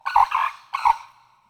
Guard Call | A sharp, single call expressing alarm.
Wattled-Crane-Alarm.mp3